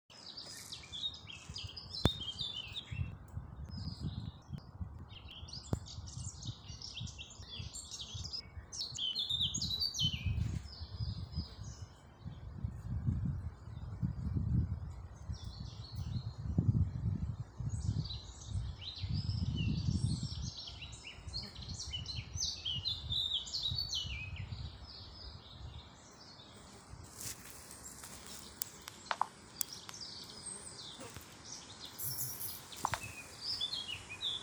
черноголовая славка, Sylvia atricapilla
Administratīvā teritorijaVecumnieku novads
СтатусПоёт